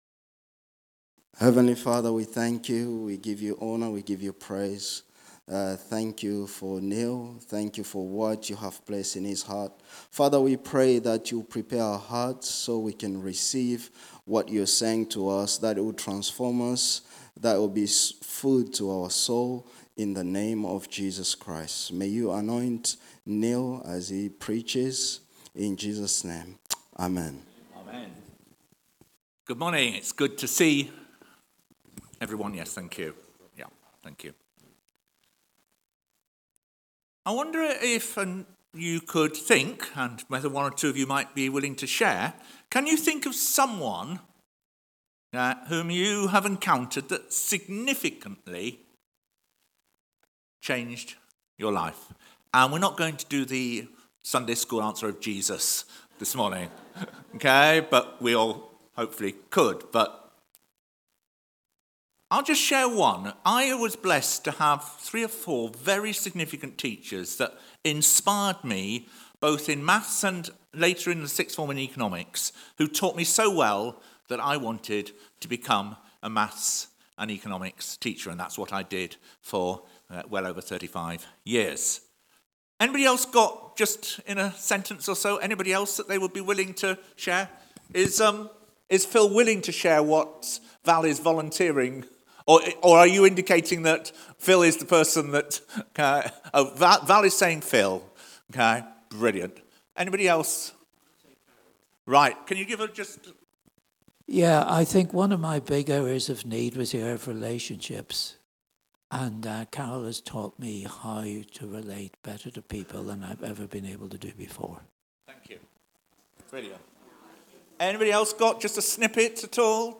Resurrection Encounters…..Continued Luke 24:36-49 The handout can be obtained by clicking on the icon at the top right. Sermon